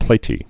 (plātē)